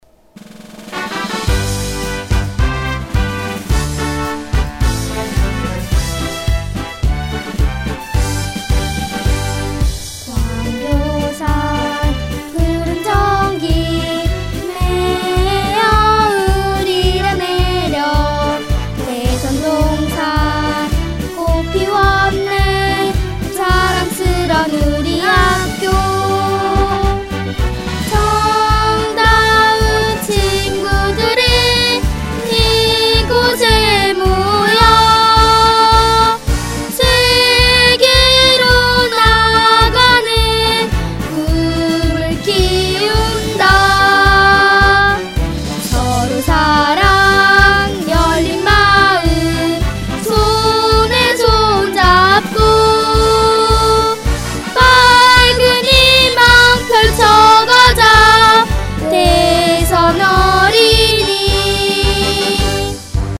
교가